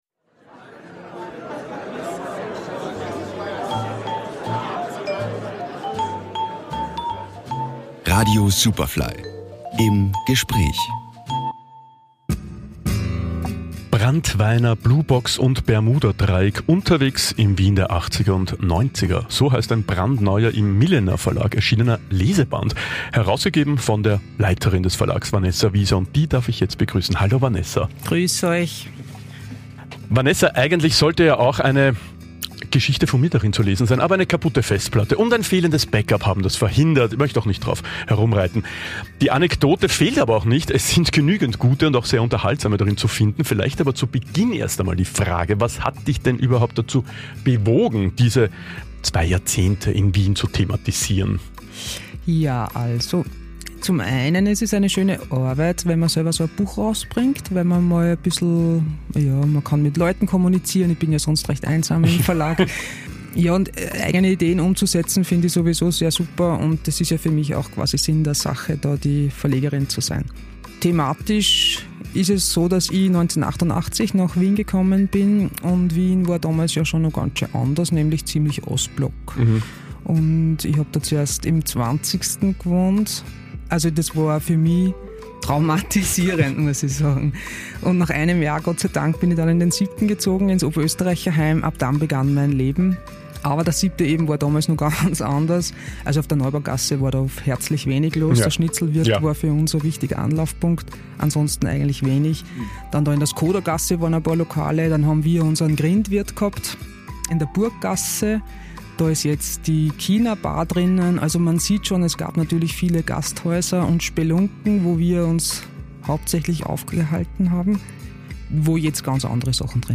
Superfly Interviews